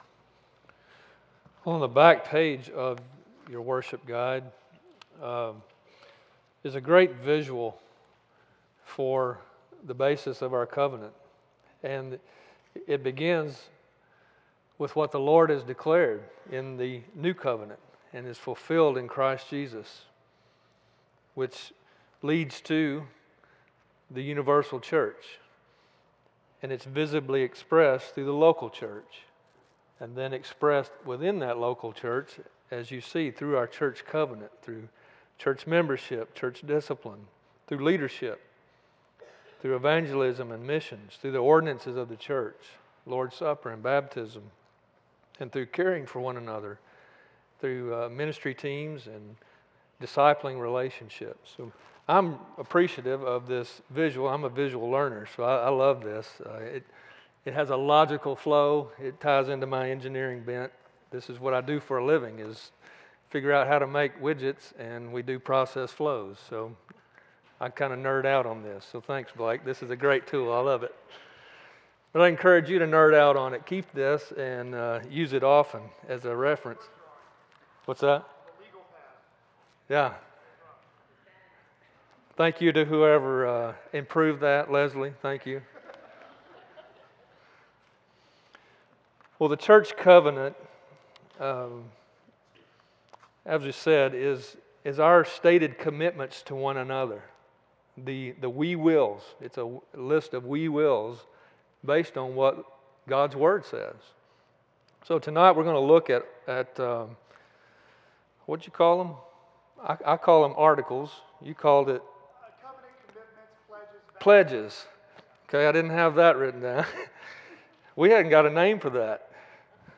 This message was delivered on Sunday evening, February 15, 2026, at Chaffee Crossing Baptist Church in Barling, AR.